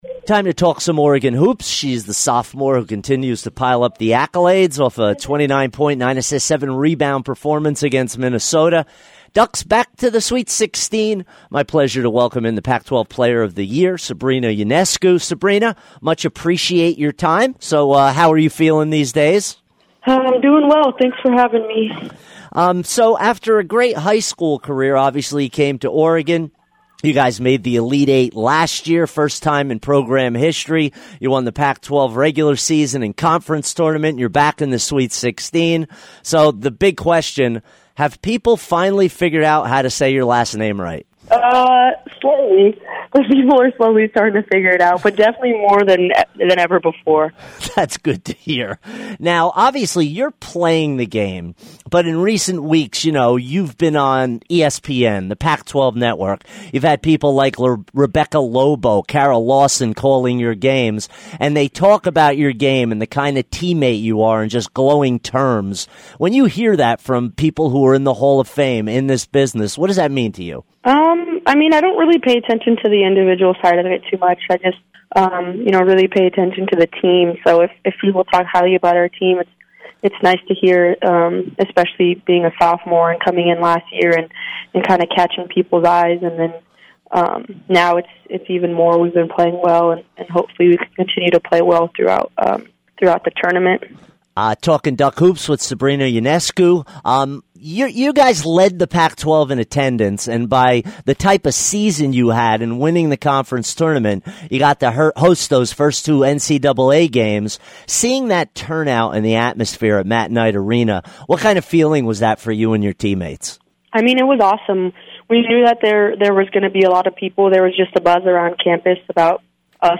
Sabrina Ionescu Interview 3-21-18